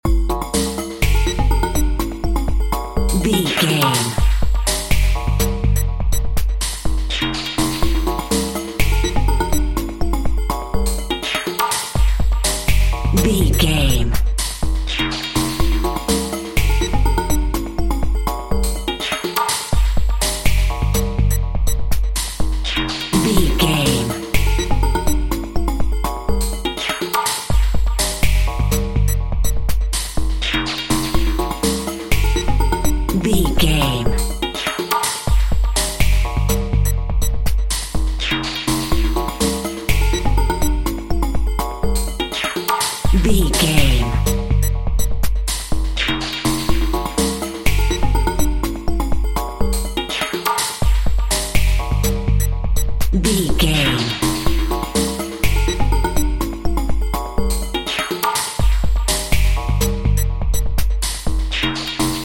Atonal
Fast
high tech
futuristic
mechanical
bouncy
drums
synthesiser
drum machine
ambient
electronic
pads
dark
industrial
glitch
synth lead
synth bass